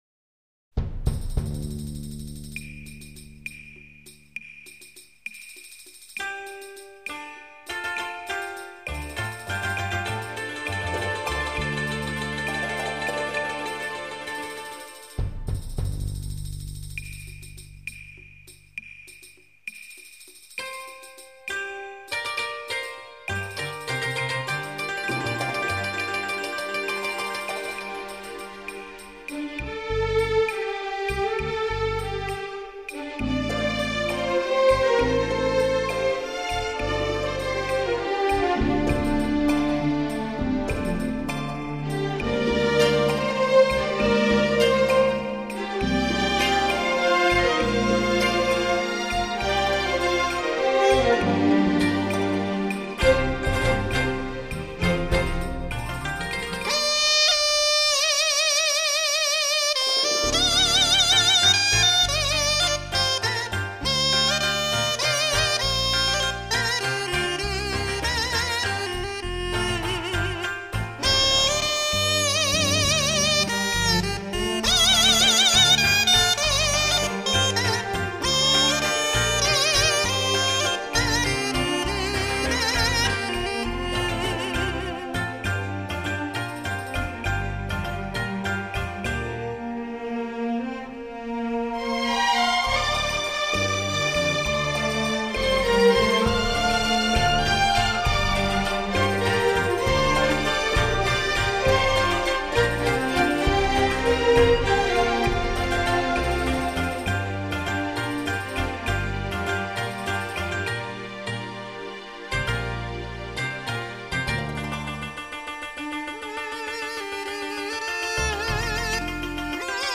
精心制作，发烧录音，这些乐曲都十分悦耳，更可以从这些音乐中欣赏到当地人民的生活气息和地方风情，是一张值得珍藏的唱片。
朝鲜族民歌